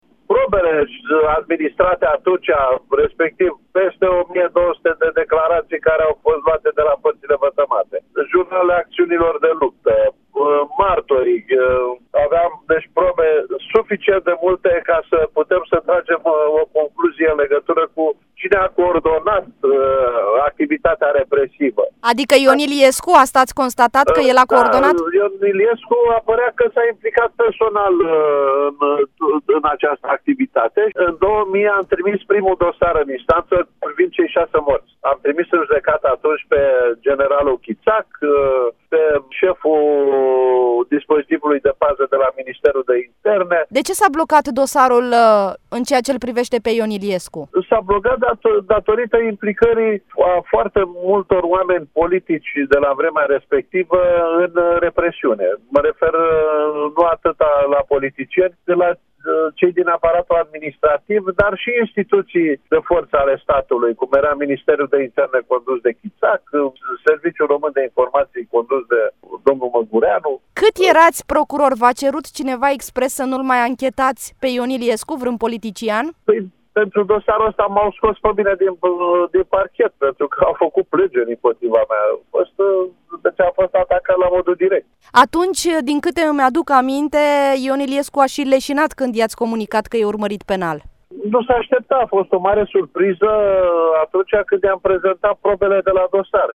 21-oct-13-Voinea-INTERVIU-de-ce-s-a-blocat-initial-dosarul-cu-Iliescu-.mp3